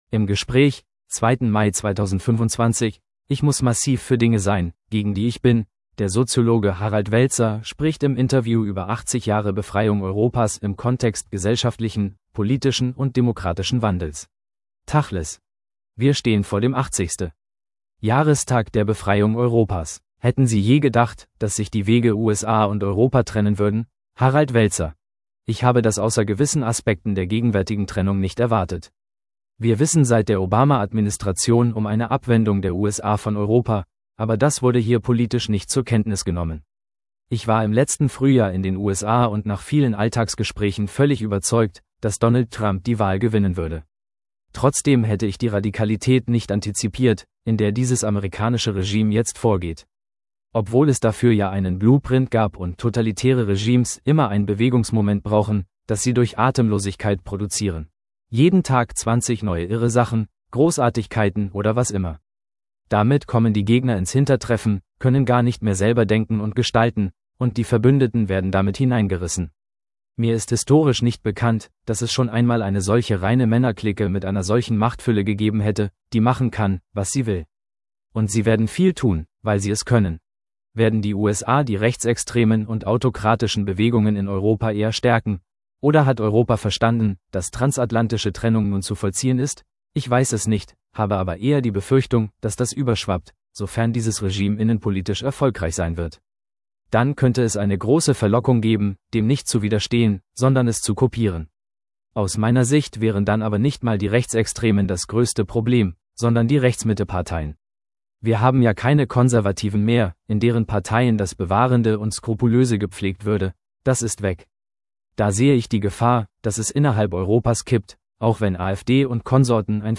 Der Soziologe Harald Welzer spricht im Interview über 80 Jahre Befreiung Europas im Kontext gesellschaftlichen, politischen und demokratischen Wandels.